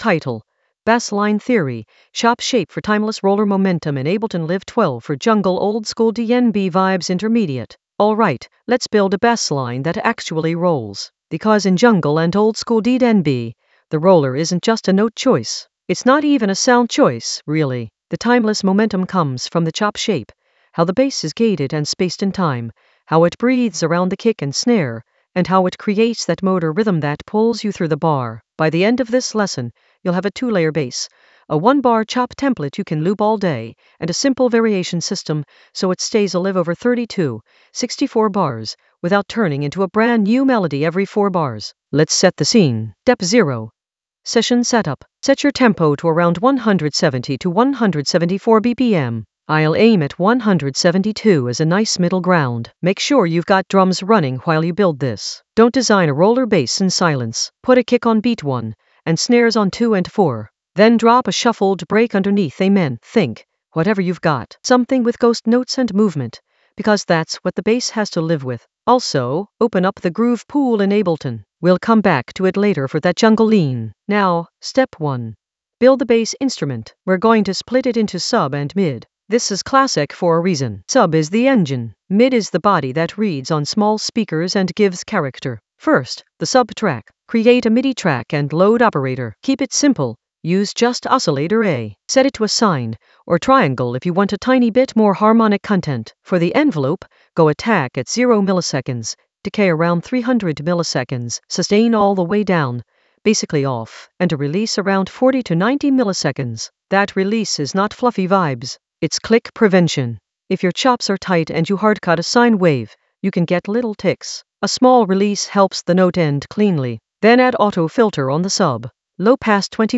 Narrated lesson audio
The voice track includes the tutorial plus extra teacher commentary.
An AI-generated intermediate Ableton lesson focused on Bassline Theory: chop shape for timeless roller momentum in Ableton Live 12 for jungle oldskool DnB vibes in the Sound Design area of drum and bass production.